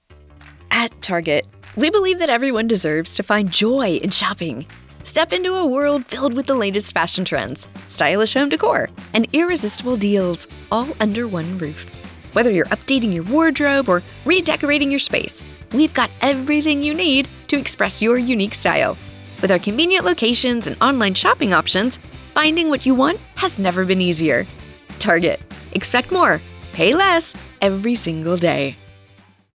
Target - Friendly, Real Person, Engaging
General American
Middle Aged
*Natural, authentic, and friendly tone